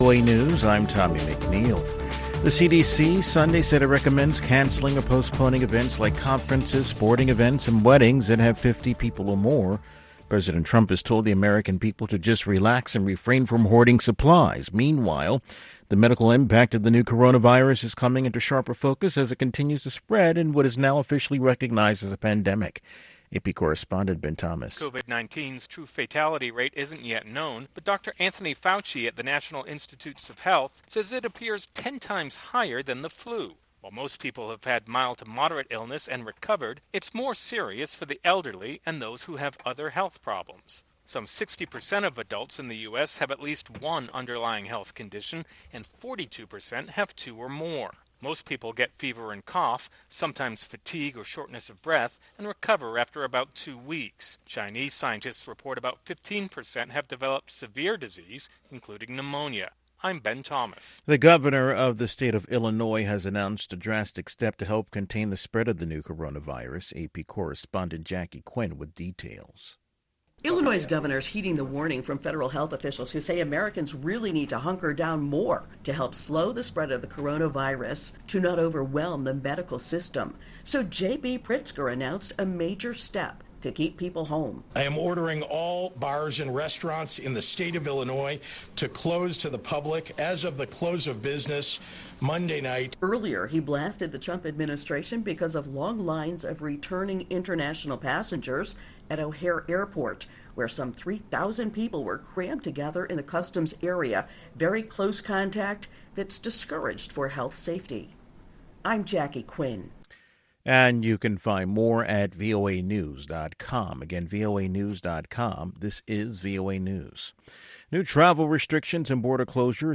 contemporary African music